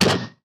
hit-v3.ogg